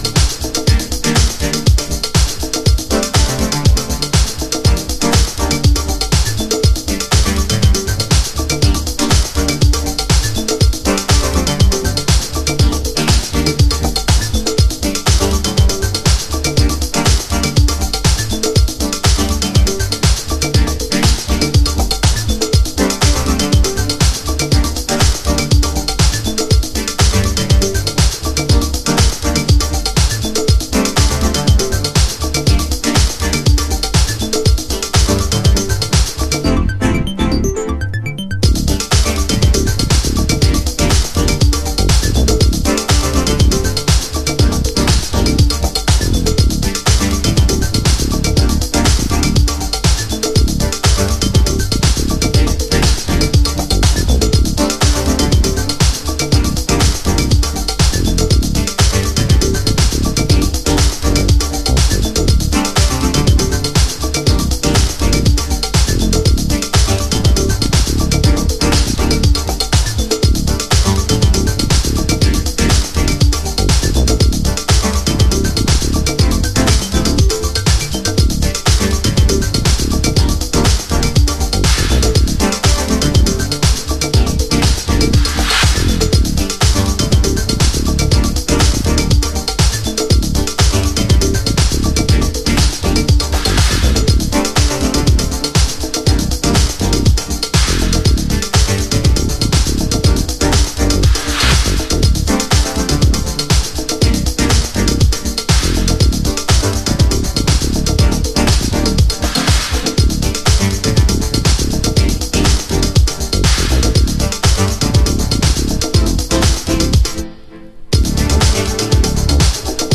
House / Techno
スペースシンセとブギーグルーヴのA1、スペースダビーハウスB1、共に2000年初頭のリリース。